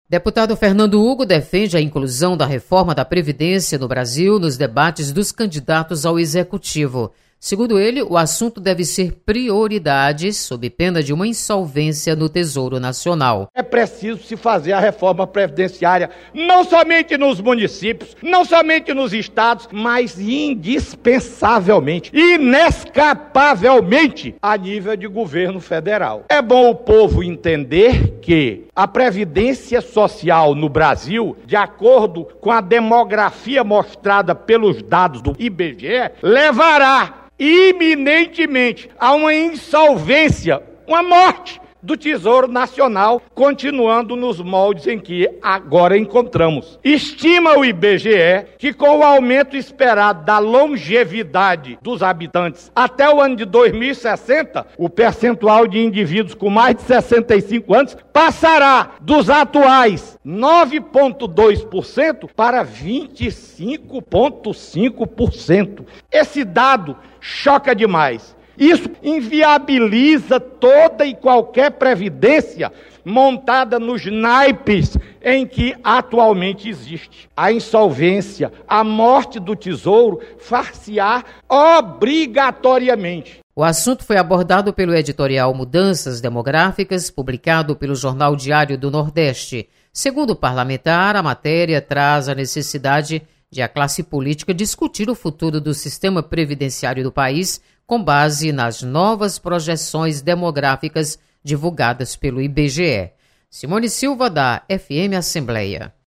Deputado Fernando Hugo sugere debate sobre reforma da previdência na pauta dos candidatos à presidência. Repórter